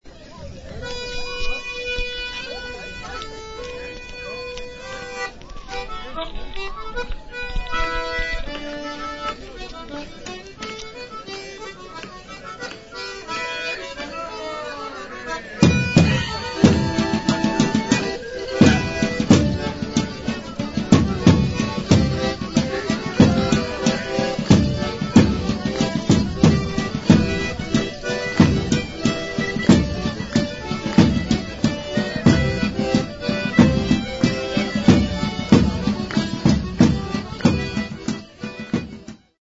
Lots of live folk music in several pubs, largely outside in their beer gardens.